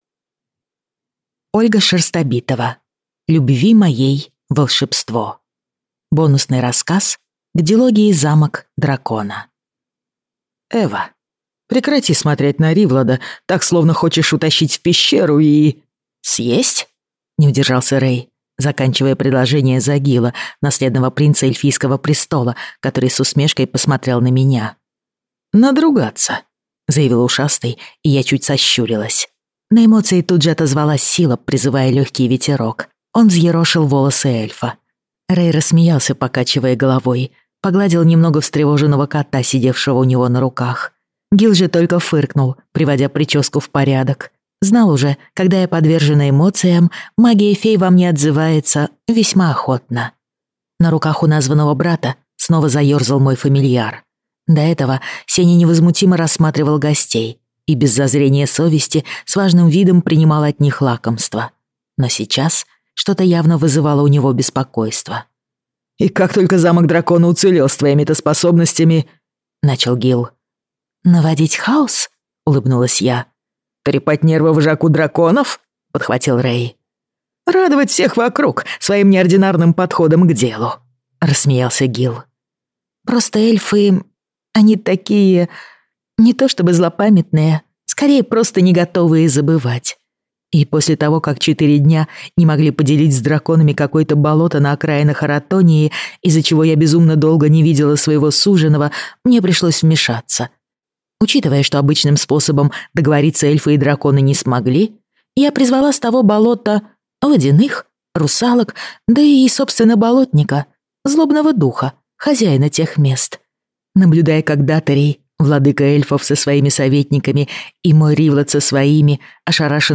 Аудиокнига Любви моей волшебство | Библиотека аудиокниг
Прослушать и бесплатно скачать фрагмент аудиокниги